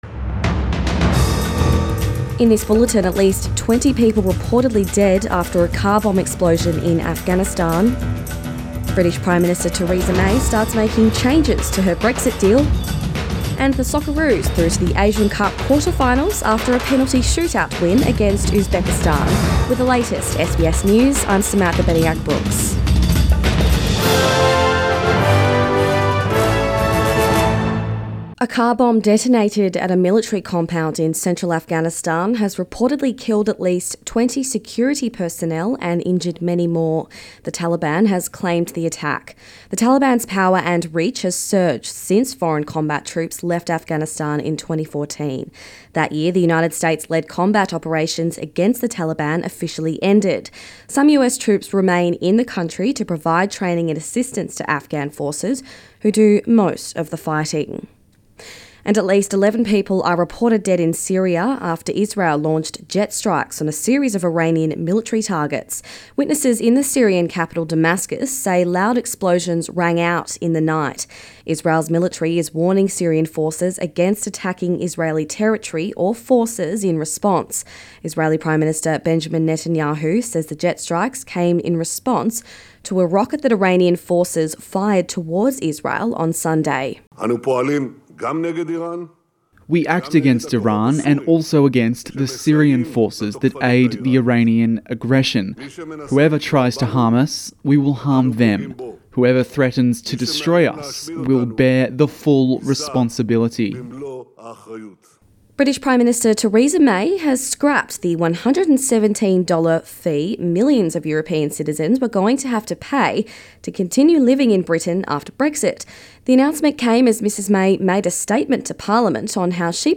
AM bulletin 22 January